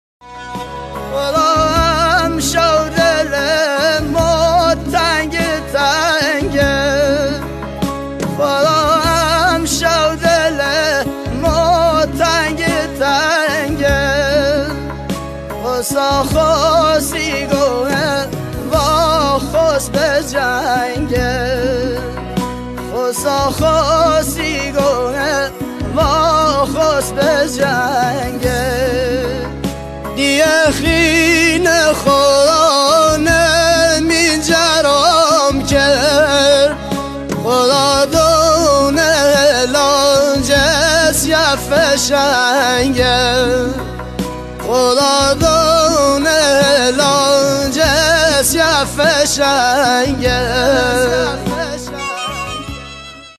لری